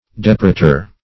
Depurator \Dep"u*ra`tor\, n. One who, or that which, cleanses.